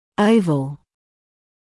[‘əuvl][‘оувл]овальный, яйцевидный